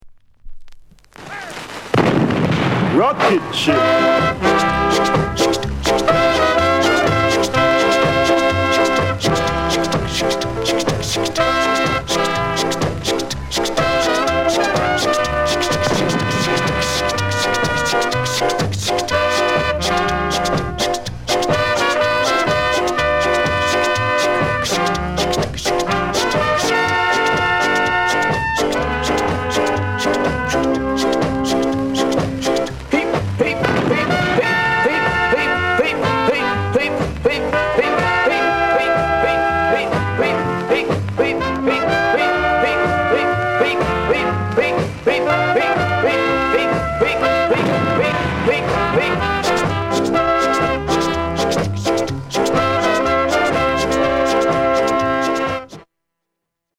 NICE SKA INST